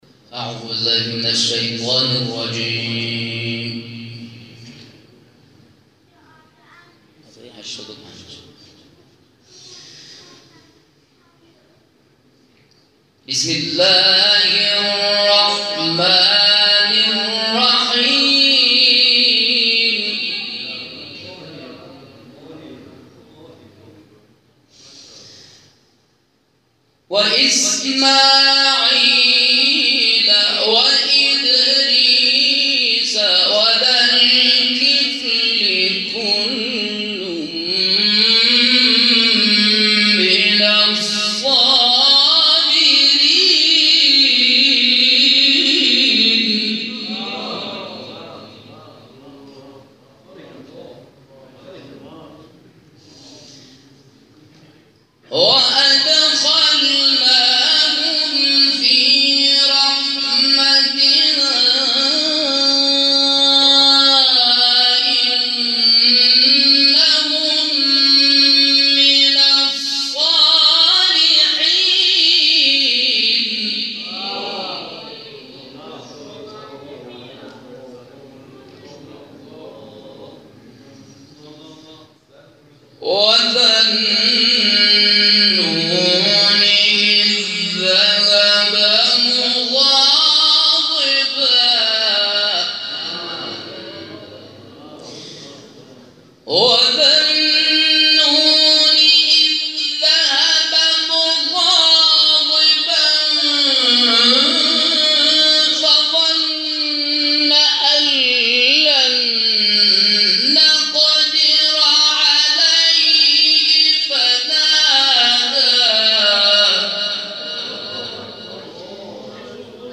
گروه فعالیت‌های قرآنی: دور چهارم مرحله مقدماتی جشنواره تلاوت‌های مجلسی، شب گذشته در حسینیه صاحب‌الزمانی(عج) تهران برگزار شد.
در ادامه تلاوت‌های منتخب این جلسه ارائه می‌شود.
تلاوت